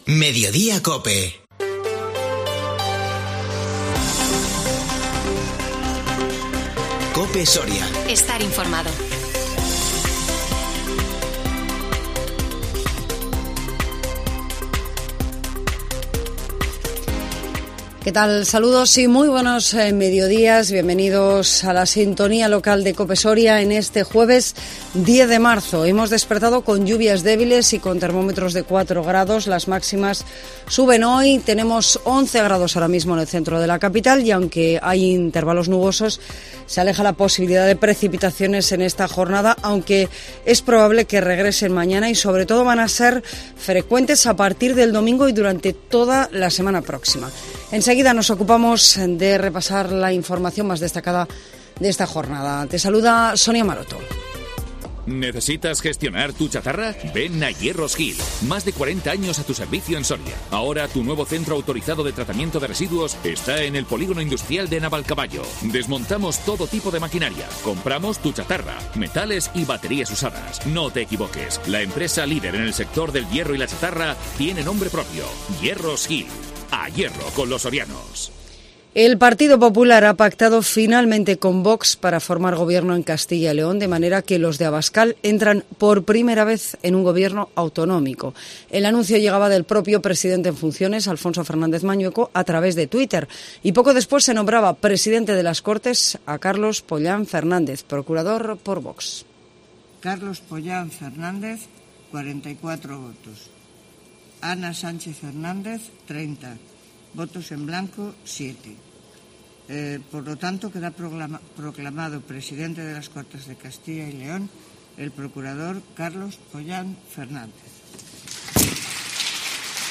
INFORMATIVO MEDIODÍA COPE SORIA 10 MARZO 2022